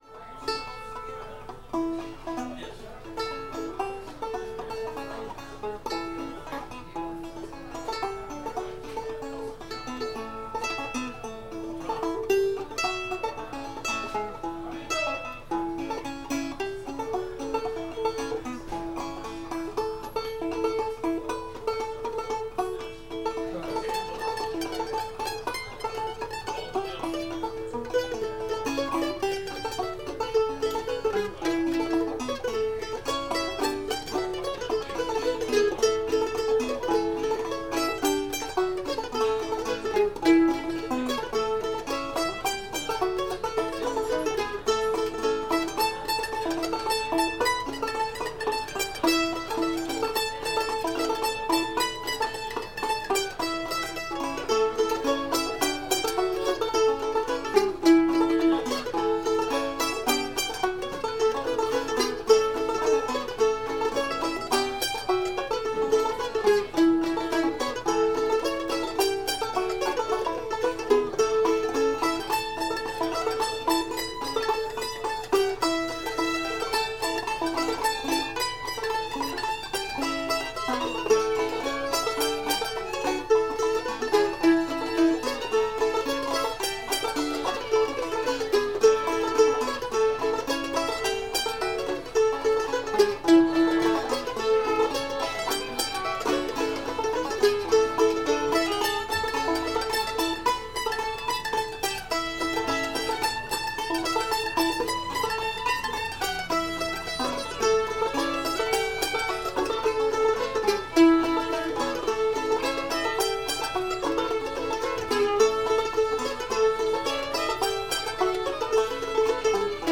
five miles of ellum wood [D]